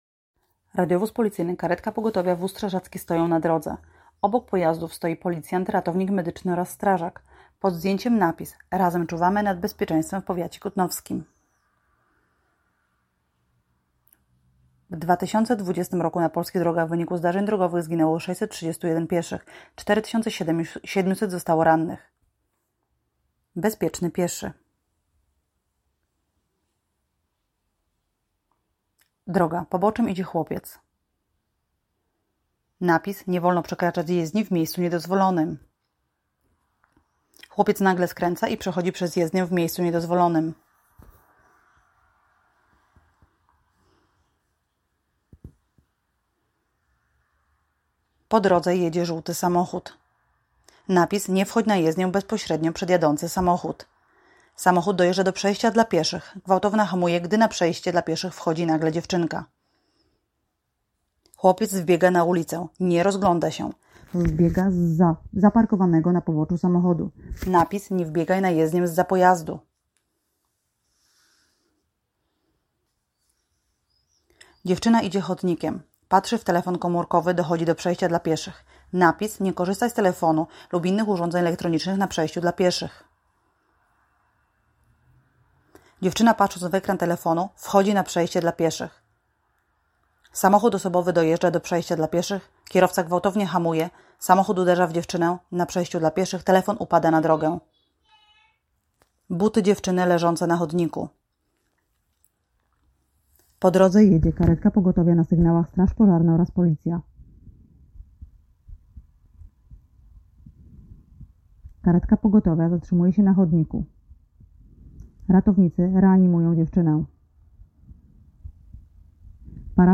Nagranie audio Audiodeskrypcja spotu